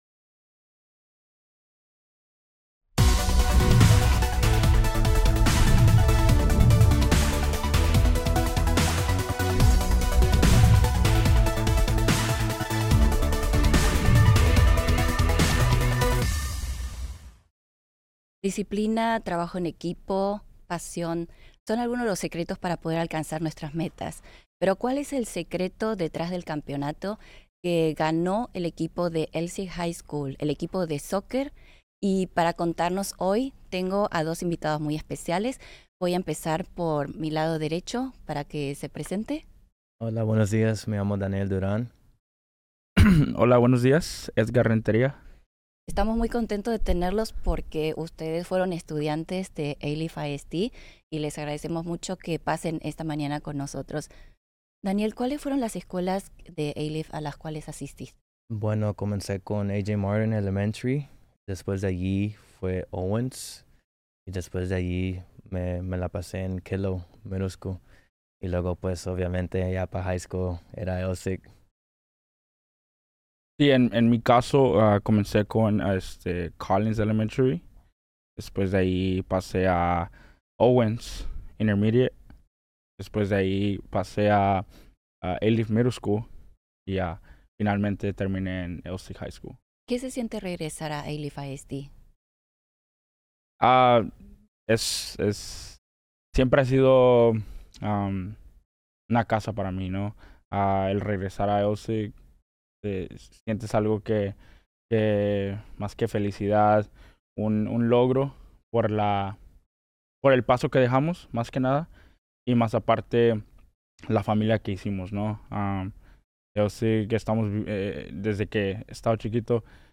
From intense matches to lifelong memories, this conversation highlights how dedication and school pride helped shape one of the most celebrated moments in Elsik soccer history.